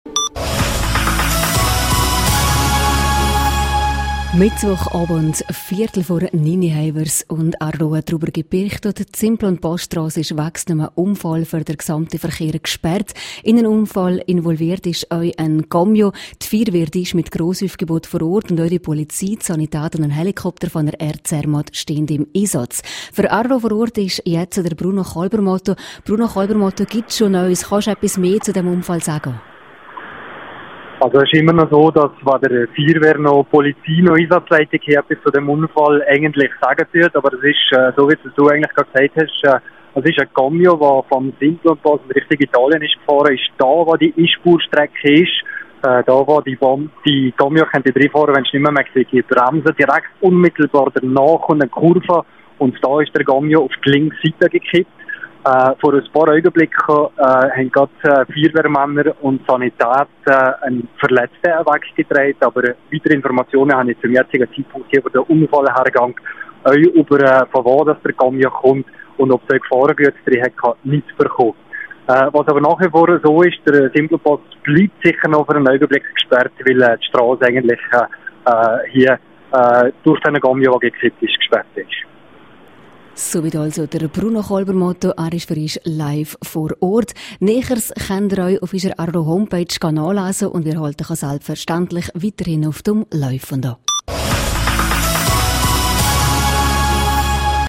13652_News.mp3